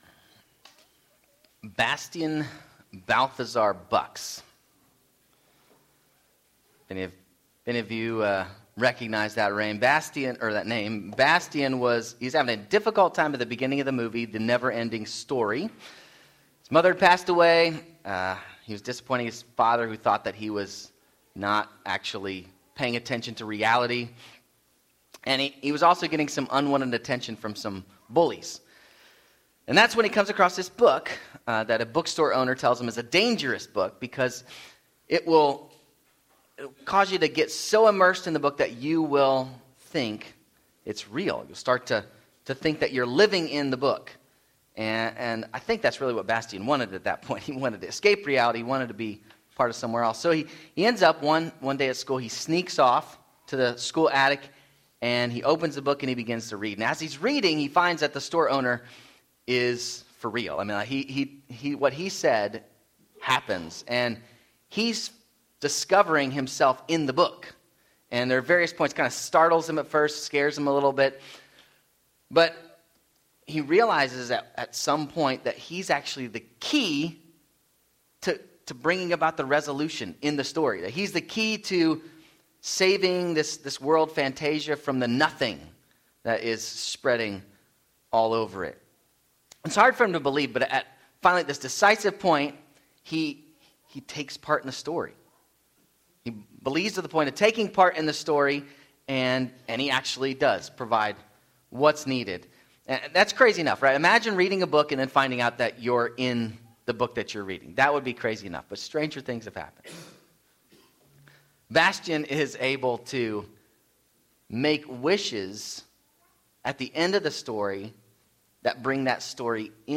Sermons
18-23 Service Type: Sunday 10:30am